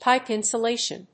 pipe+insulation.mp3